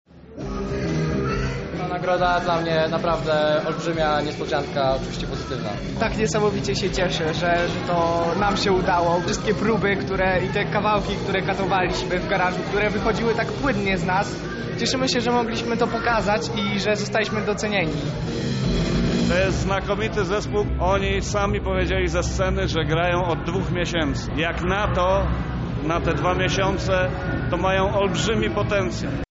O swoich wrażeniach mówią uczestnicy konkursu